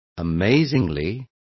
Complete with pronunciation of the translation of amazingly.